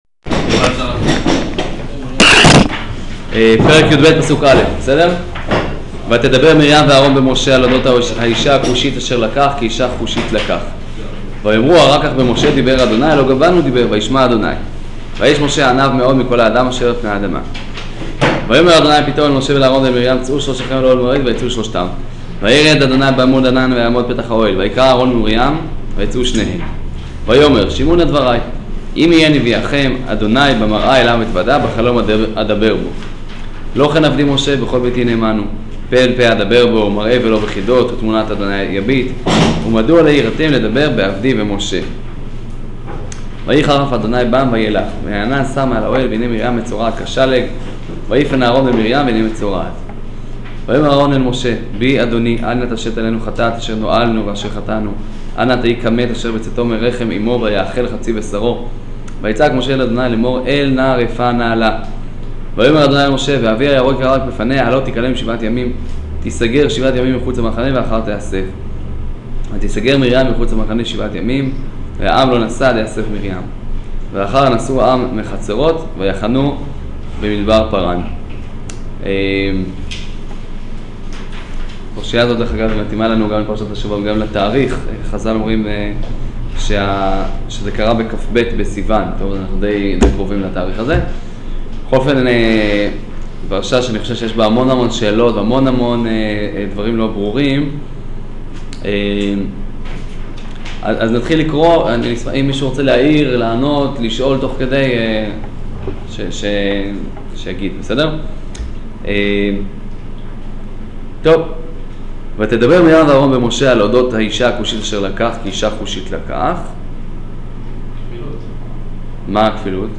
שיעור פרשת בהעלותך